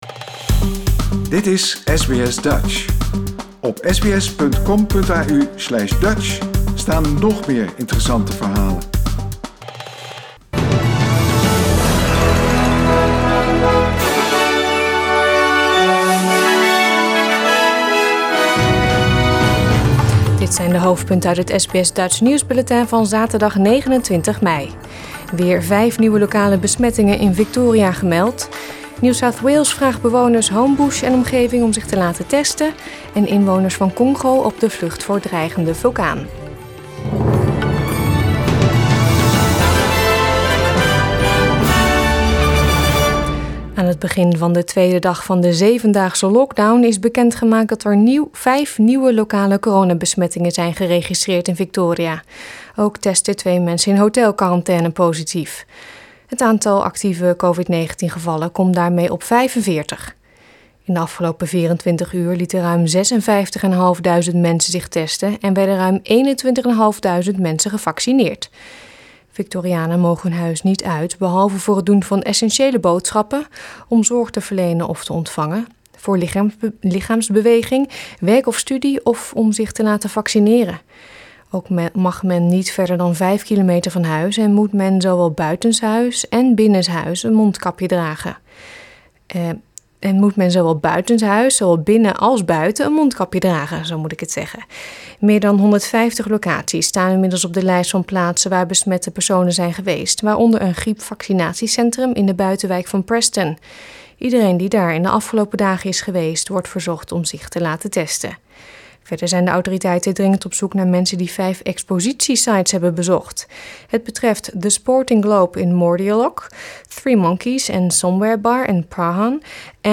Nederlands/Australisch SBS Dutch nieuwsbulletin van zaterdag 29 mei 2021